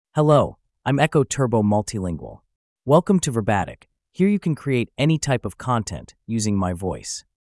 MaleEnglish (United States)
Echo Turbo Multilingual is a male AI voice for English (United States).
Voice sample
Echo Turbo Multilingual delivers clear pronunciation with authentic United States English intonation, making your content sound professionally produced.